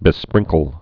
(bĭ-sprĭngkəl)